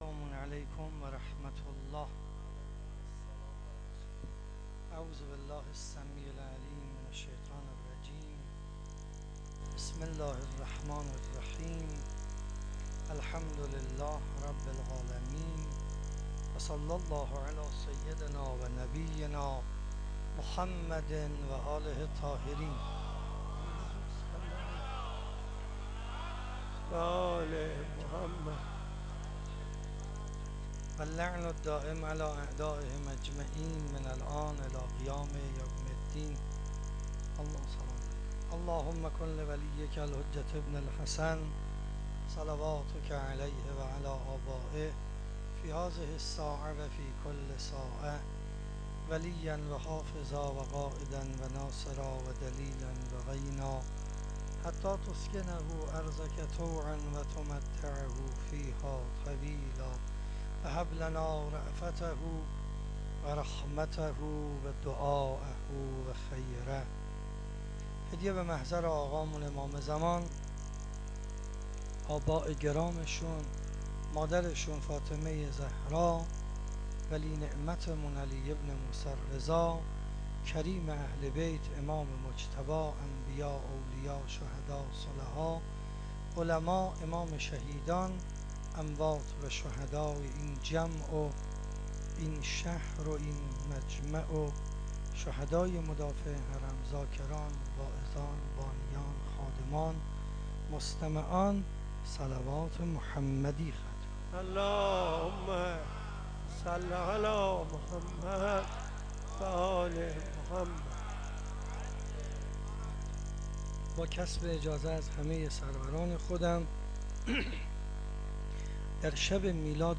شب 15 رمضان 97- مسجد حضرت قاسم ابن الحسن علیهما السلام